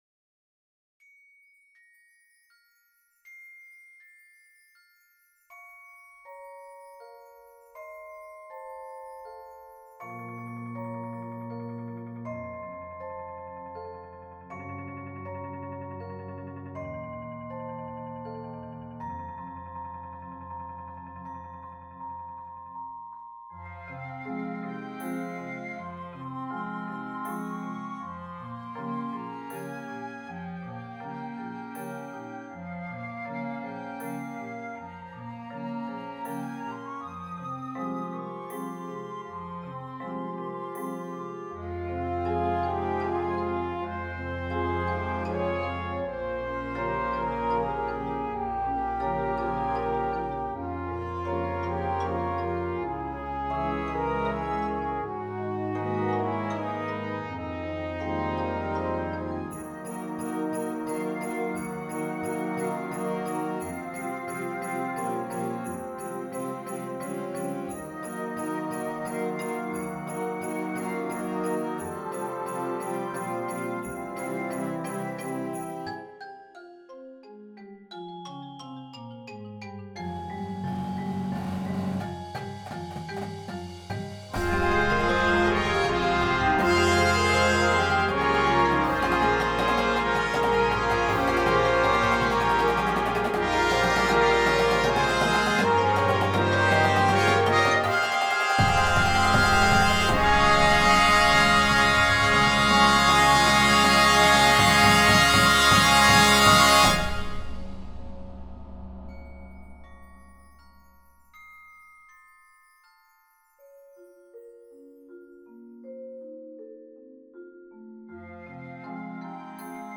Winds and Percussion